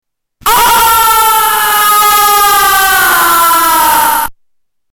Male scream in echo chamber
Tags: Science/Nature The Echo soundboard Echo Echolocation Sound Sound